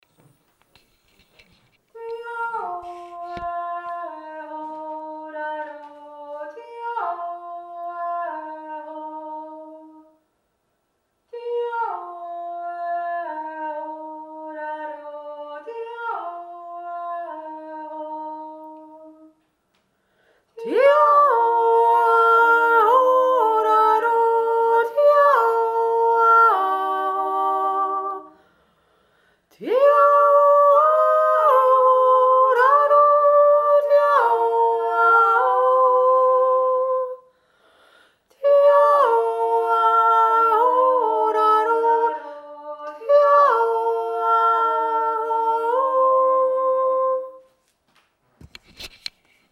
feenjodler-3.mp3